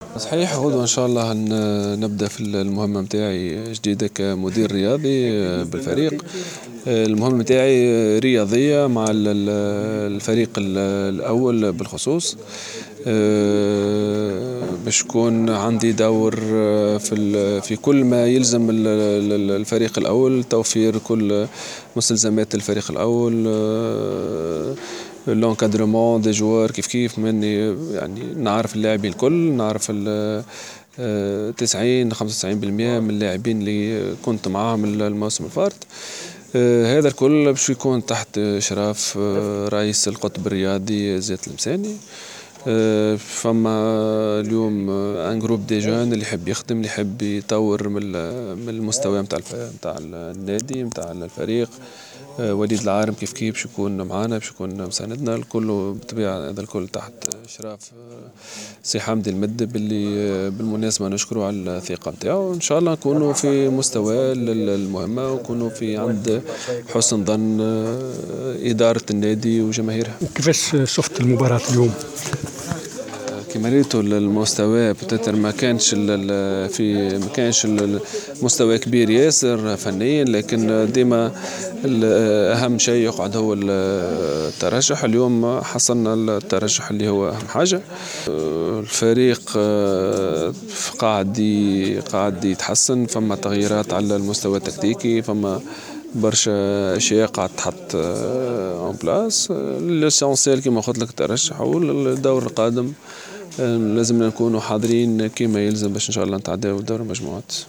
وسيلاقي الترجي المريخ السوداني في الدور القادم .إستمع إلى الحوارات: